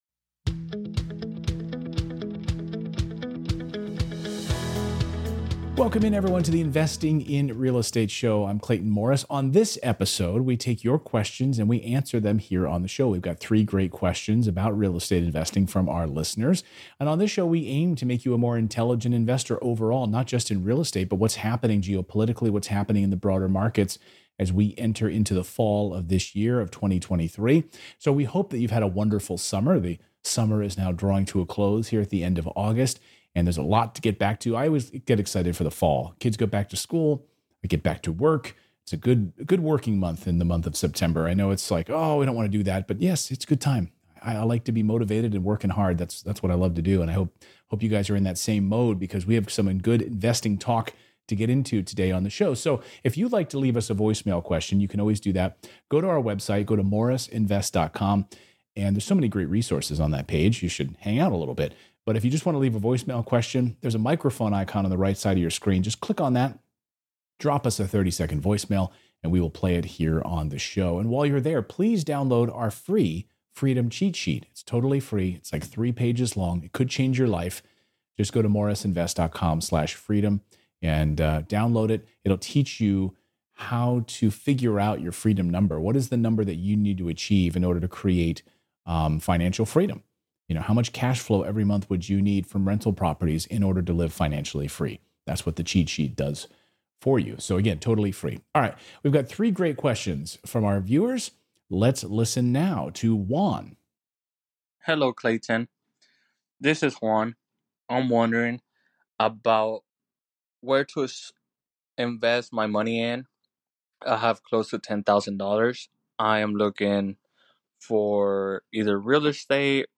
Today's first caller has $10k in reserves. What's the best way to get started investing with that amount of capital?